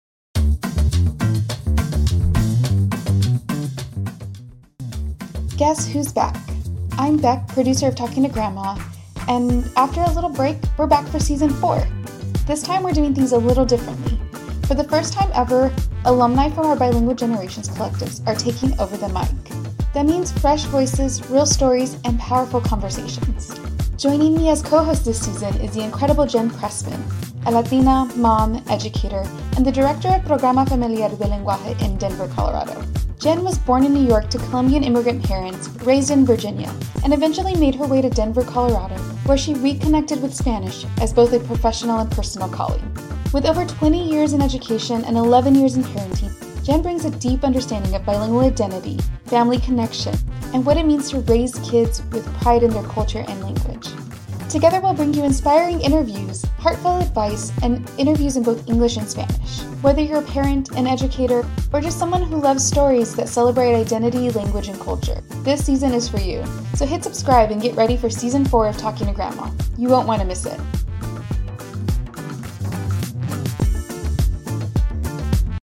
Season 4 Teaser: Fresh Voices, Real Stories, Powerful Conversations
After a short break, Talking to Grandma returns for Season 4...this time with a twist. For the first time ever, alumni from our Bilingual Generations Collectives are taking over the mic, bringing fresh perspectives, authentic stories, and powerful conversations.